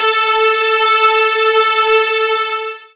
voiTTE64008voicesyn-A.wav